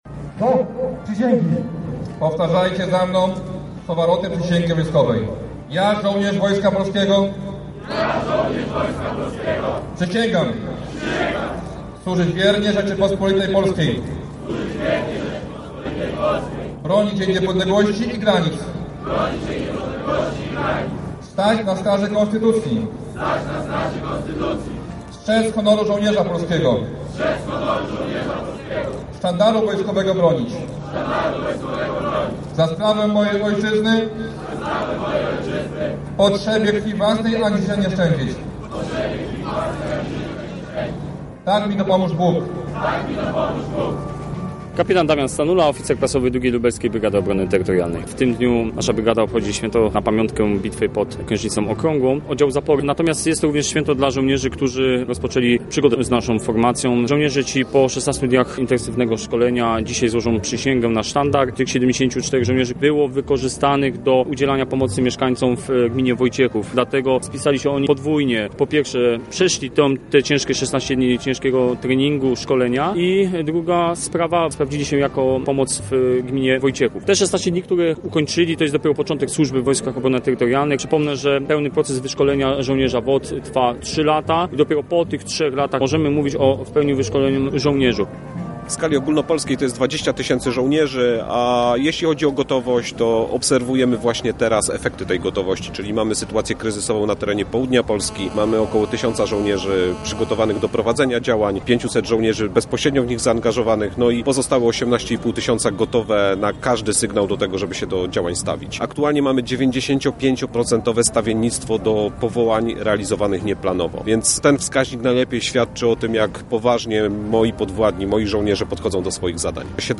Na placu Zamkowym był także nasz reporter, który obserwował wydarzenie:
Relacja